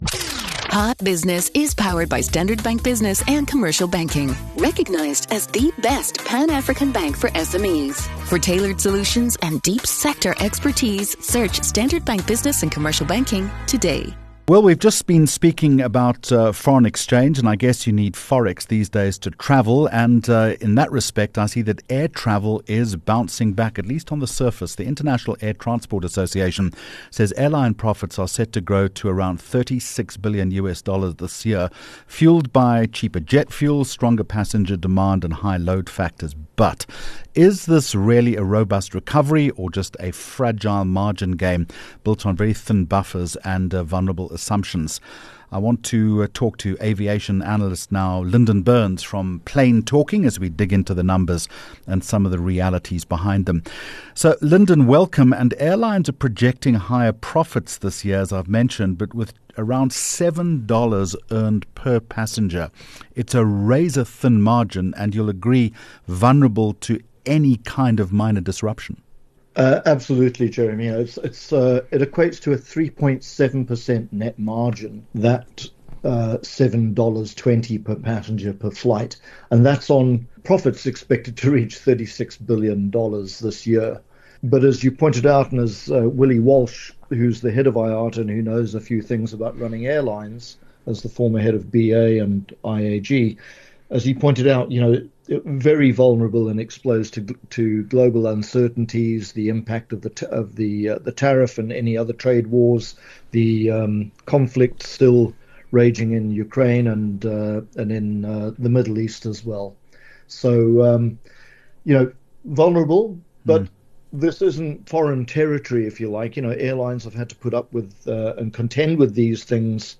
4 Jun Hot Business Interview